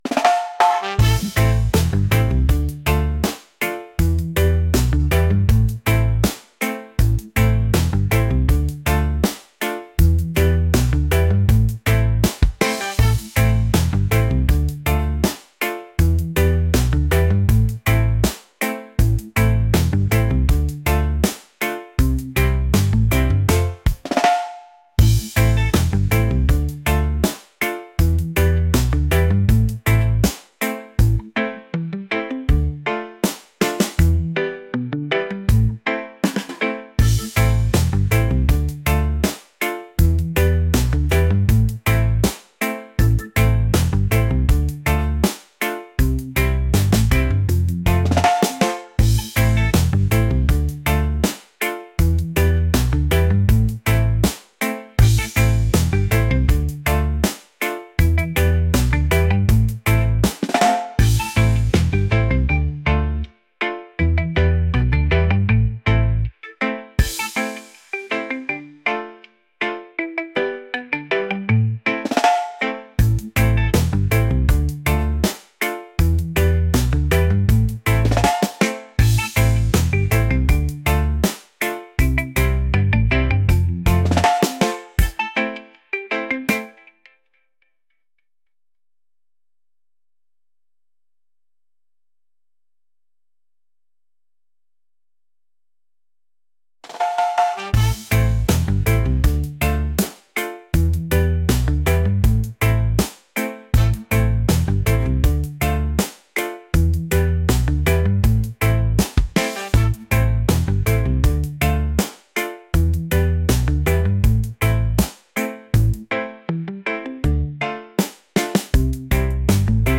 reggae | groovy | laid-back